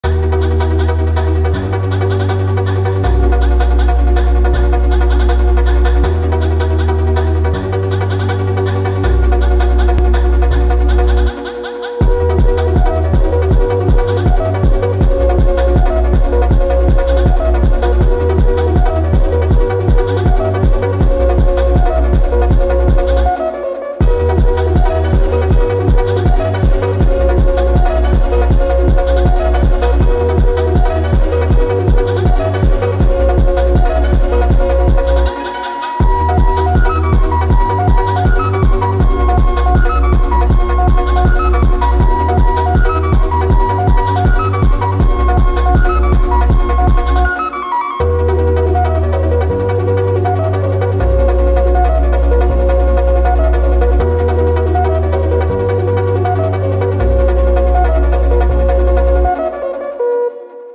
فانک
ماشینی